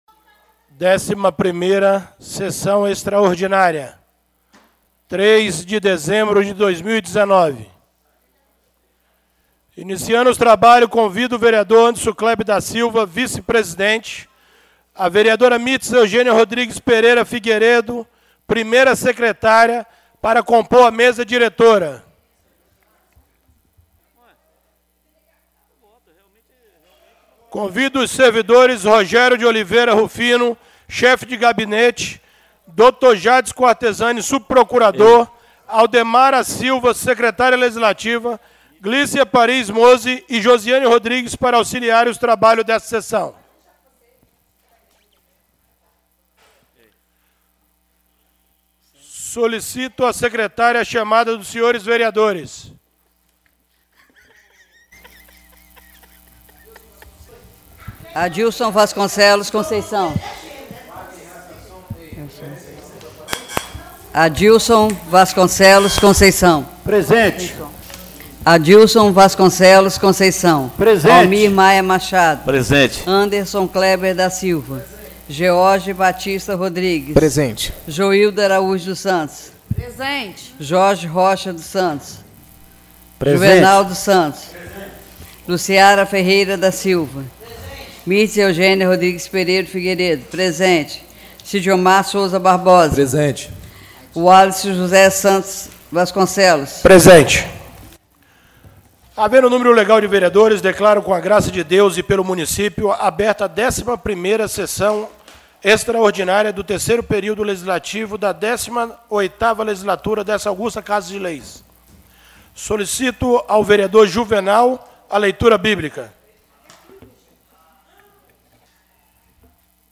11ª Sessão Extraordinária do dia 03 de dezembro de 2019